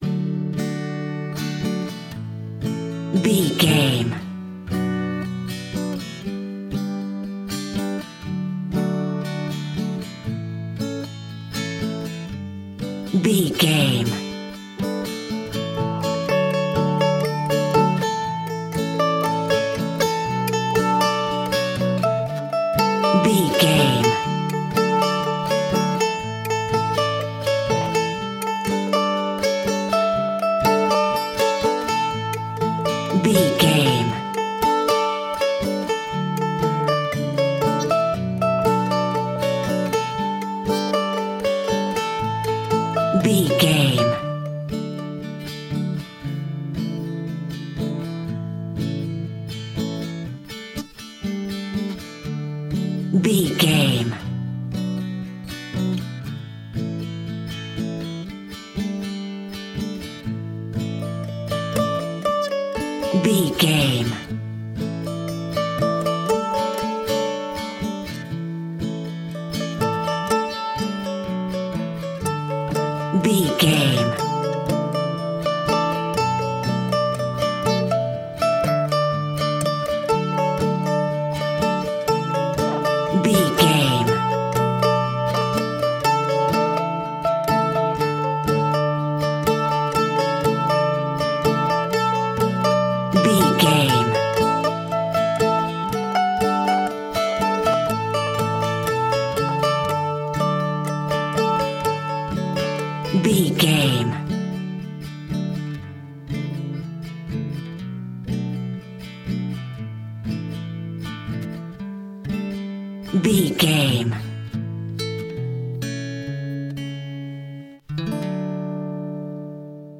Mandolin and Acoustic Guitar Pop.
Ionian/Major
pop rock
indie pop
fun
energetic
uplifting